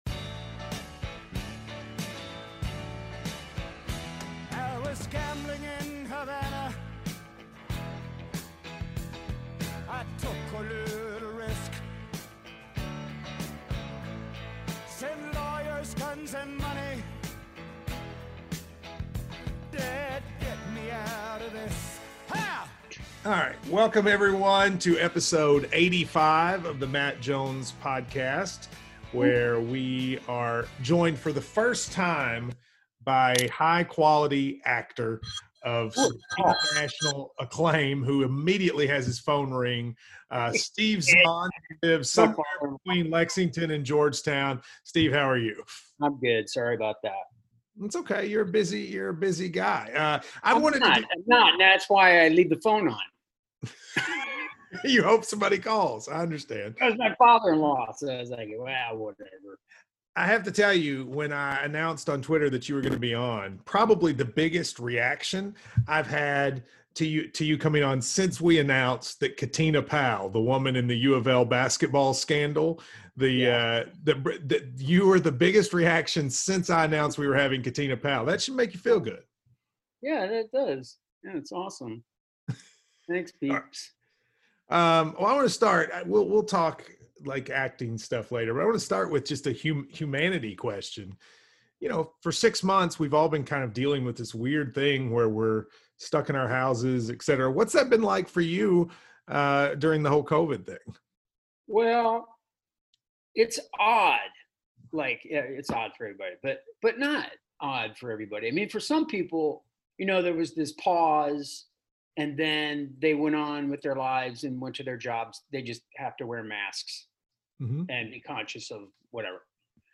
extended one-on-one interviews with guests from in and outside the sports world.